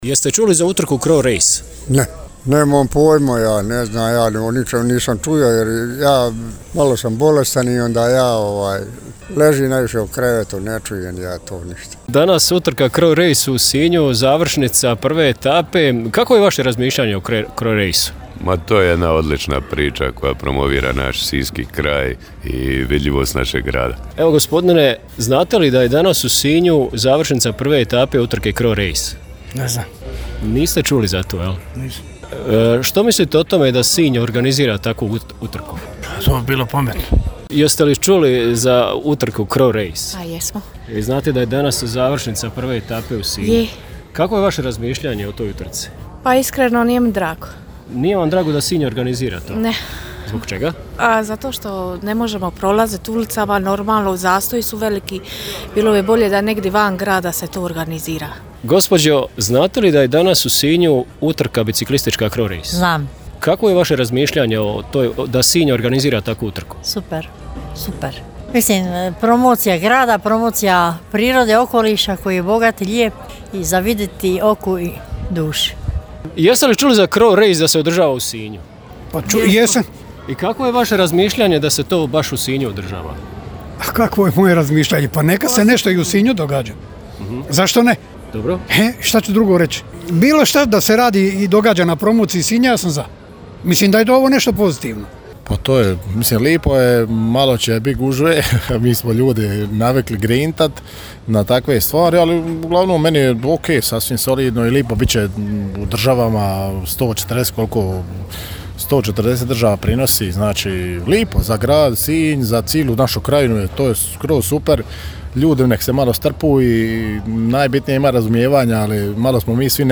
Prohladno, ali ugodno jutro vaš je reporter iskoristio za šetnju po sinjskoj Pijaci s ciljem dobiti glas naroda o današnjoj prvoj etapi međunarodne biciklističke utrke CRO Race, čiji je cilj upravo Grad Pod Kamičkom. Pitao sam slučajne prolaznike jesu li uopće čuli za utrku CRO Race i kako razmišljaju o činjenici da Grad Sinj sudjeluje u organizaciji ovog događaja. Anketirano je ukupno deset naših sugrađana i sugrađanki.